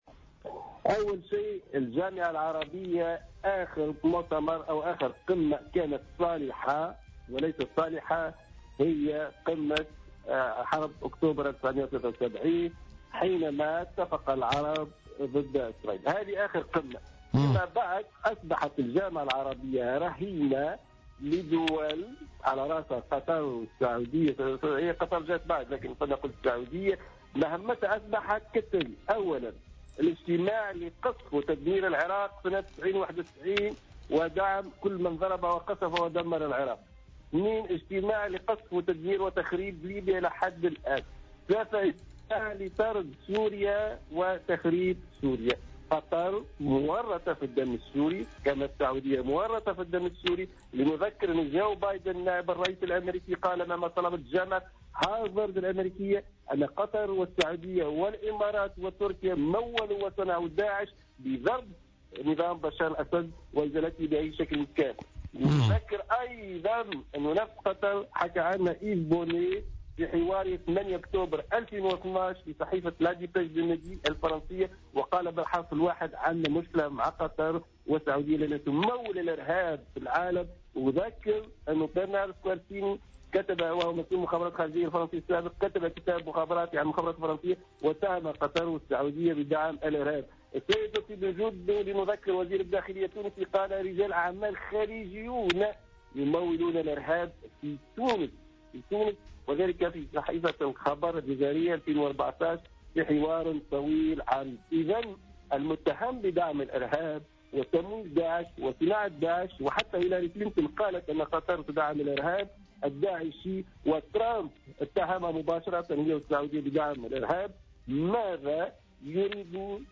وأضاف في مداخلة له اليوم في برنامج "بوليتيكا" أن الجامعة أصبحت بعد قمة حرب أكتوبر 1973 جامعة وهابية سعودية قطرية، مهمتها مجرّد الاجتماع لدعم تدمير العراق وليبيا وسوريا، وفق تعبيره.